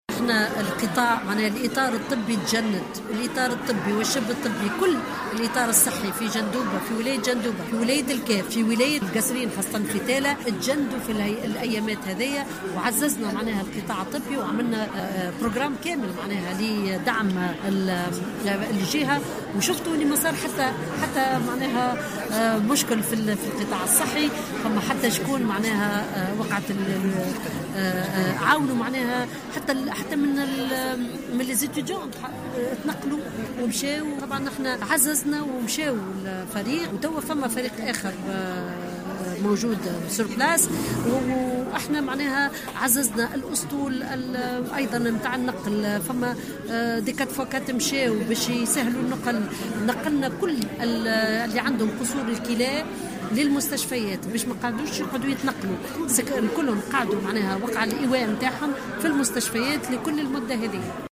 أكدت وزيرة الصحة، سميرة مرعي في تصرح للجوهرة "اف ام" اليوم أن وزارتها عملت على تعزيز الإطار الطبي والشبه طبي في ولايات جندوبة والقصرين والكاف عقب موجة البرد التي تعيش على وقعها البلاد حاليا.